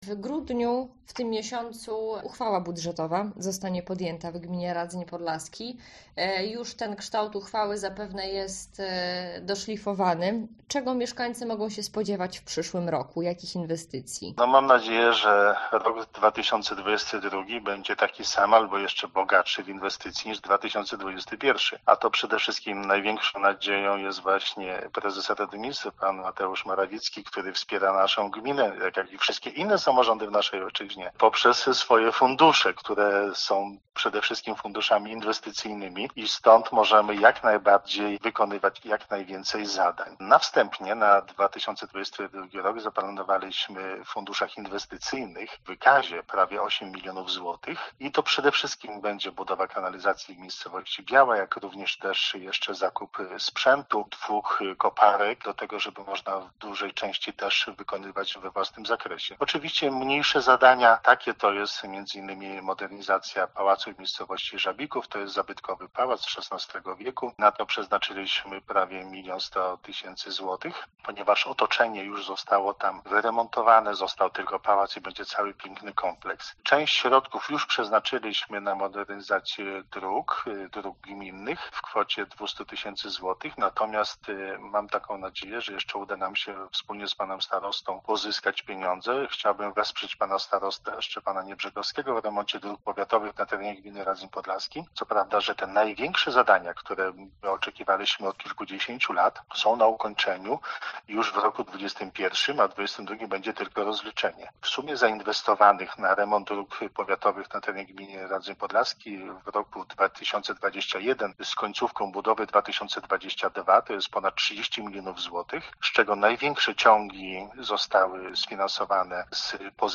O planach budżetowych Katolickiemu Radiu Podlasie powiedział wójt gminy Radzyń Podlaski Wiesław Mazurek.